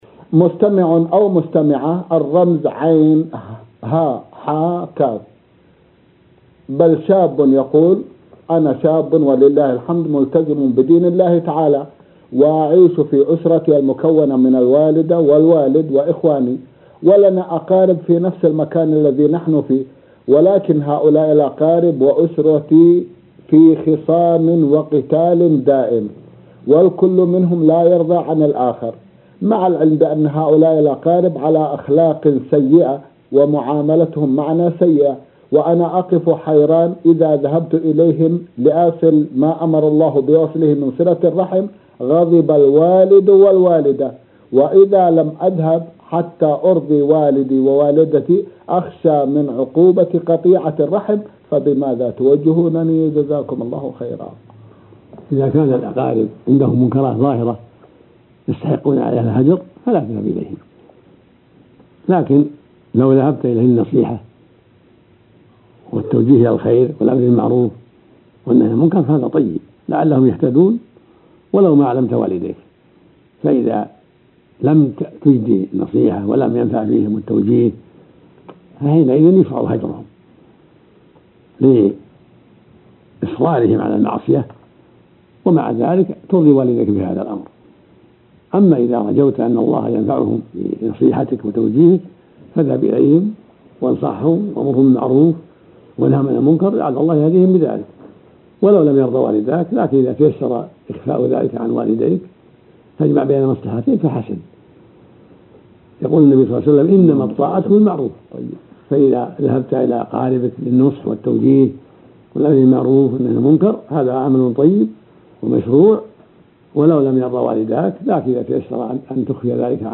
Quelle: Audioaufnahme aus نور على الدرب .